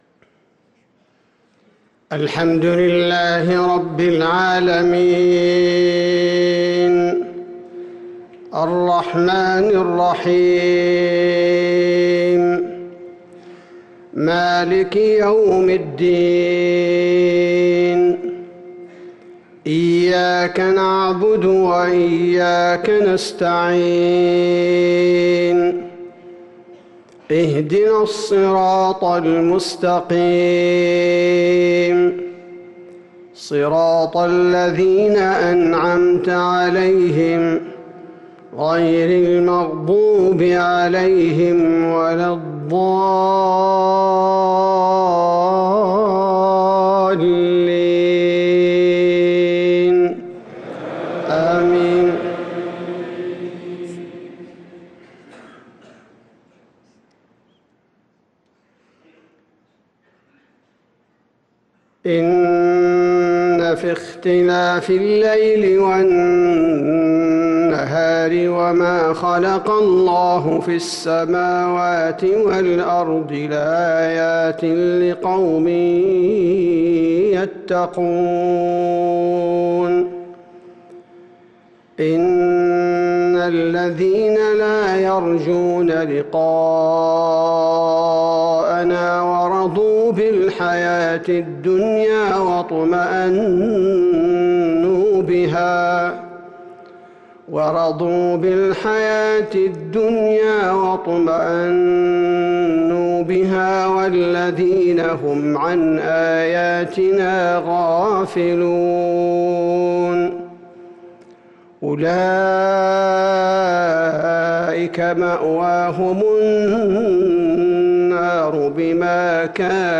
صلاة المغرب للقارئ عبدالباري الثبيتي 13 رجب 1444 هـ
تِلَاوَات الْحَرَمَيْن .